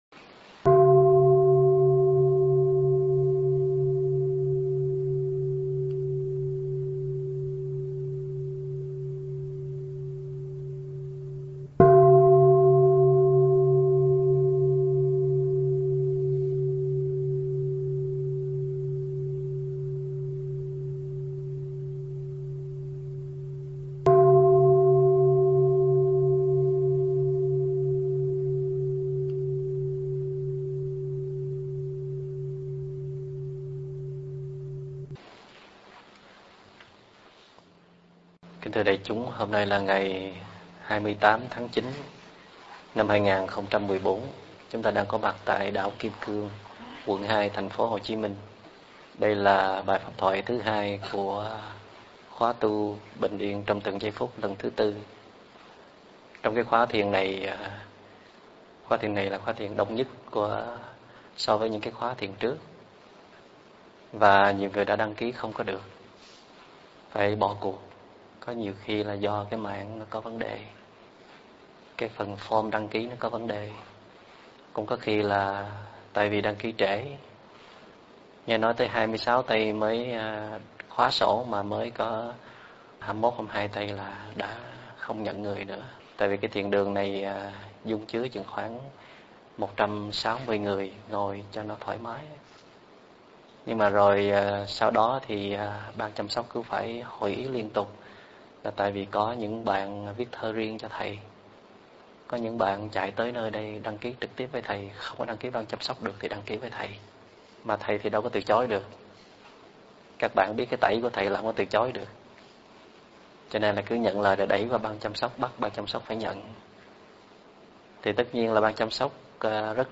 giảng ở đảo Kim Cương, quận 2 ngày 28 tháng 9 năm 2014
Mp3 Thuyết Pháp